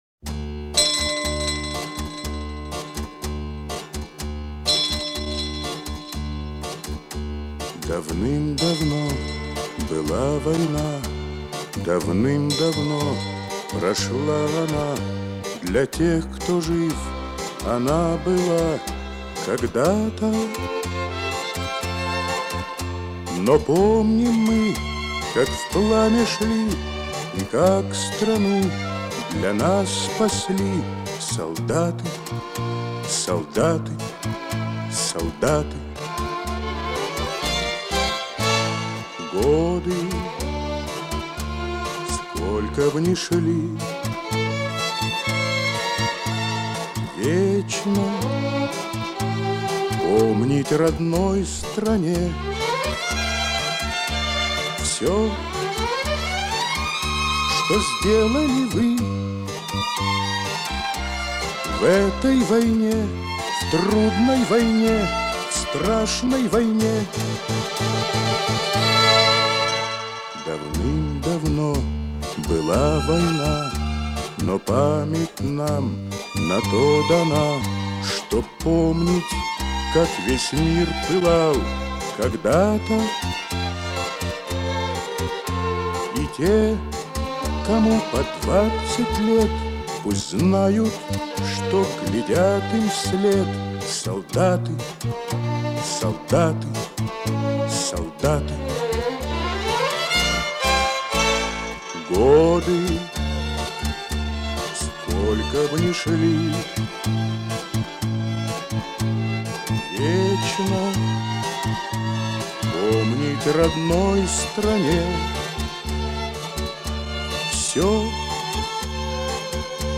Вот песня с CD диска